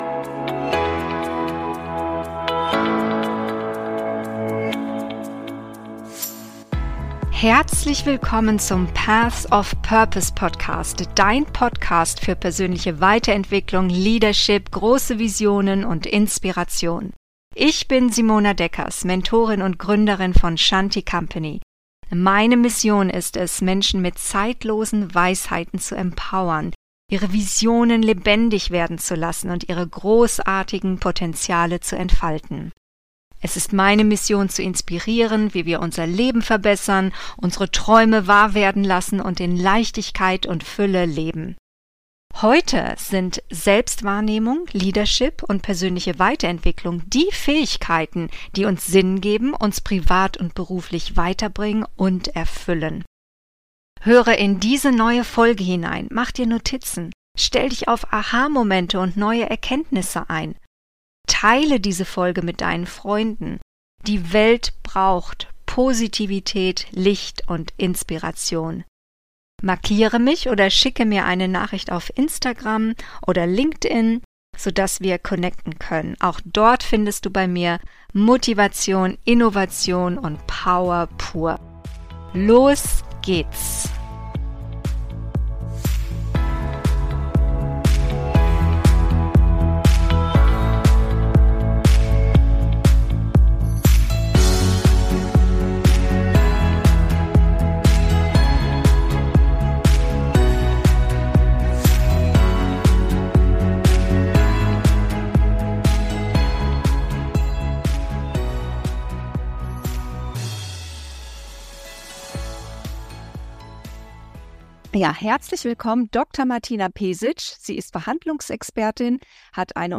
Die Kunst der erfolgreichen Verhandlung - Interview